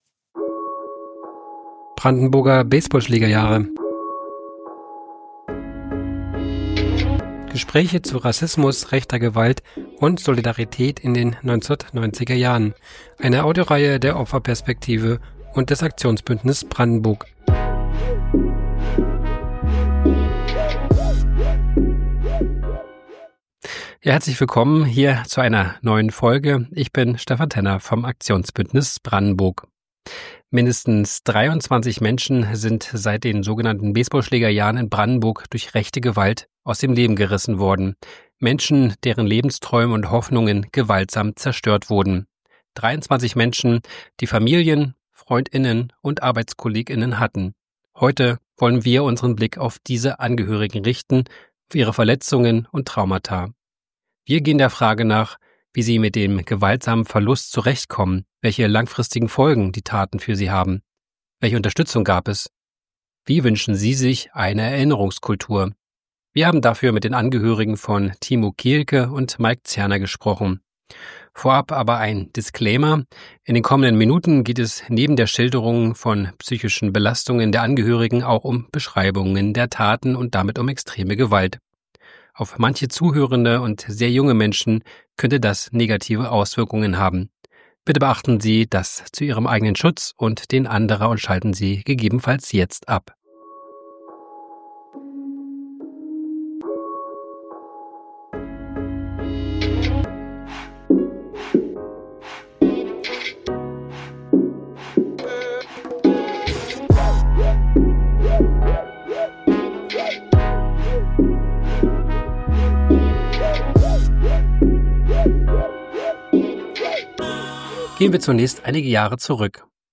Gespräche zu Rassismus, rechter Gewalt und Solidarität in den 1990er Jahren